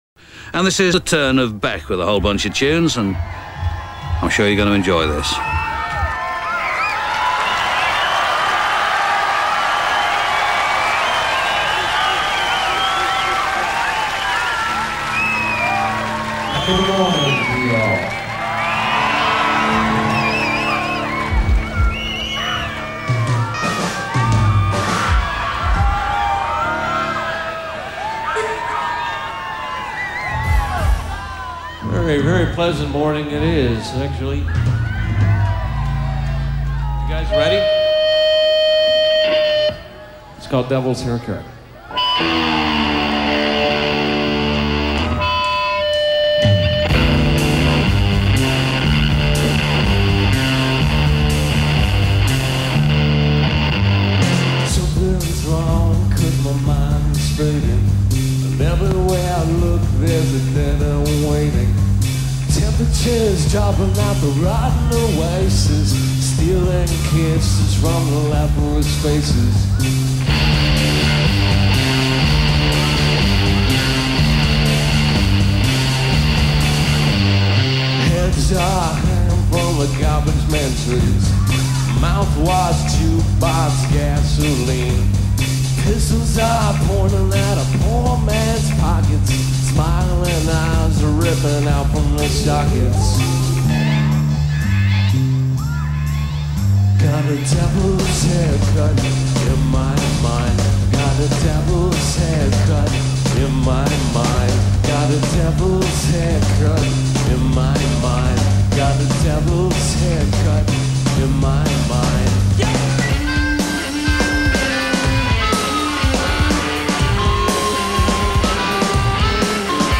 Lo-Fi